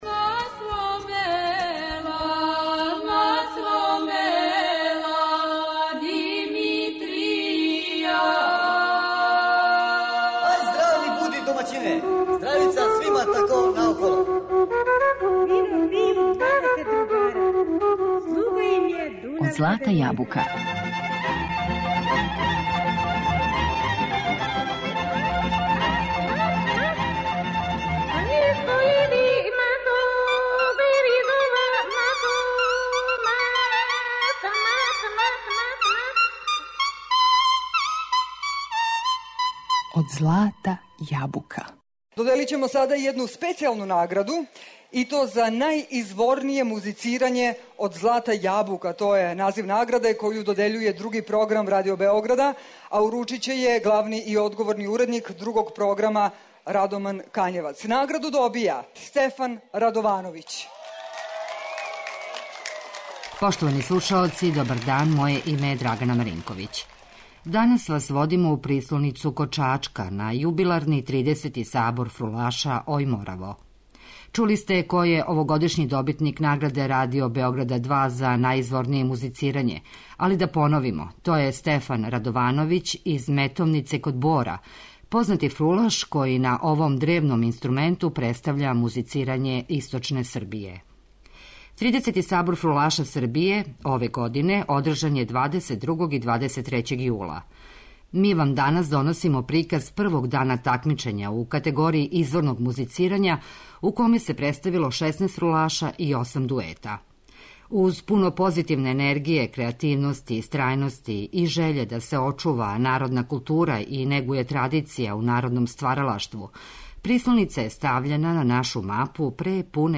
Јубиларни тридесети Сабор народног стваралаштва 'Ој, Мораво'